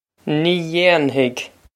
Nee yain-hig
This is an approximate phonetic pronunciation of the phrase.